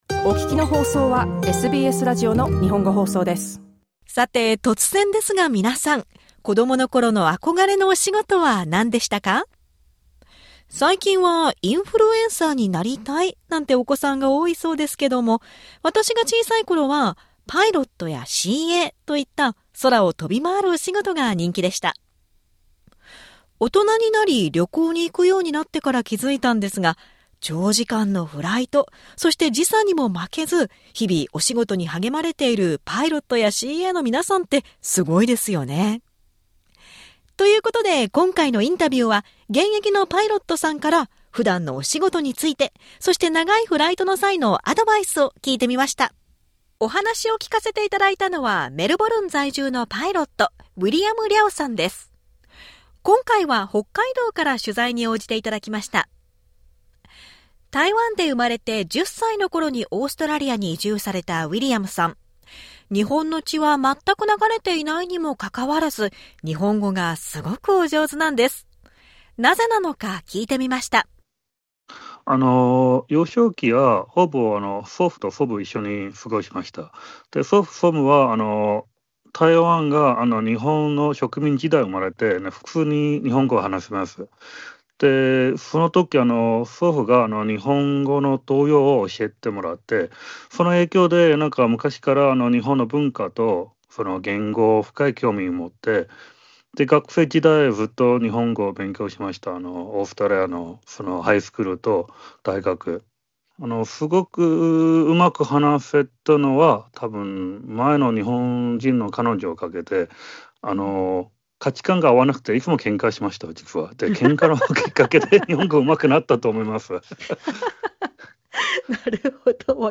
In this interview, he shares with us how he became so fluent in Japanese, what made him pursue his career as a pilot and any tips on how to survive long-haul flights.